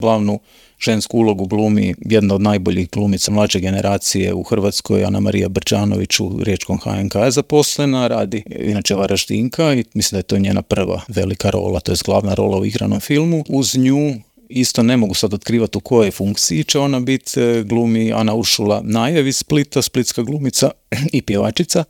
Povodom najave filma, ugostili smo ga u Intervjuu Media servisa, te ga za početak pitali u kojoj je fazi film naziva BETA.